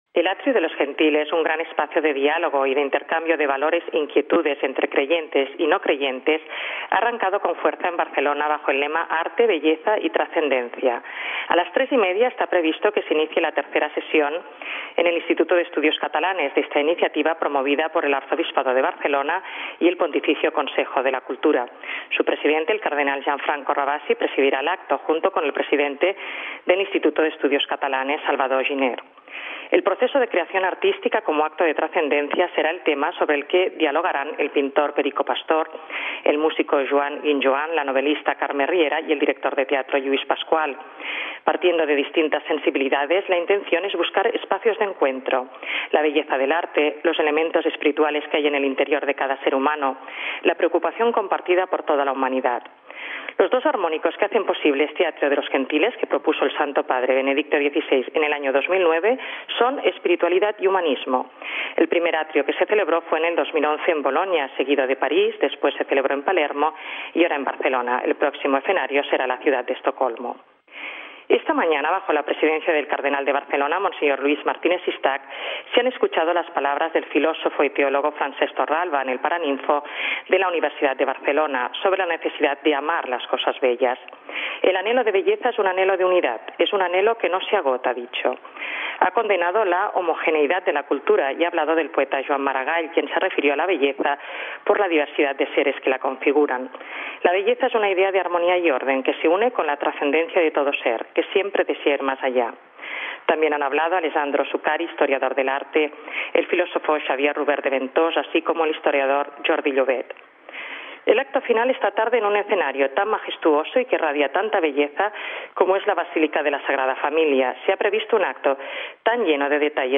Nuestra corresponsal en Barcelona